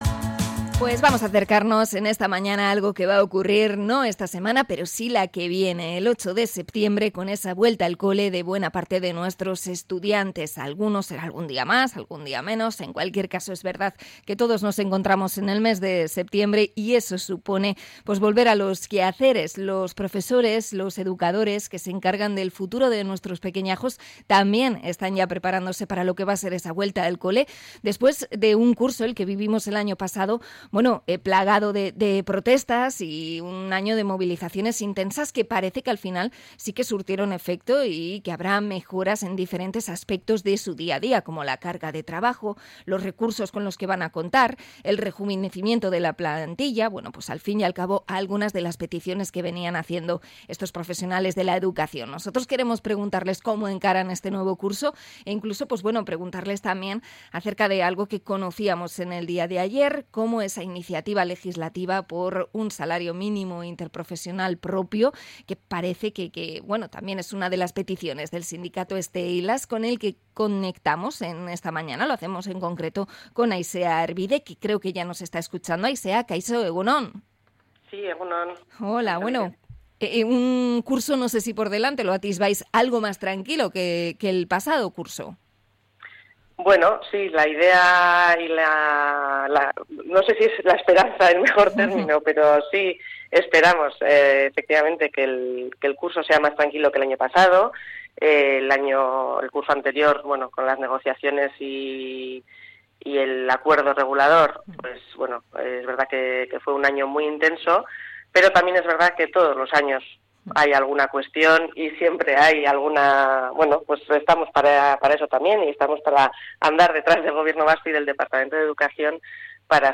Entrevista a sindicato STEILAS por la vuelta al cole de septiembre